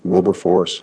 synthetic-wakewords
ovos-tts-plugin-deepponies_Barack Obama_en.wav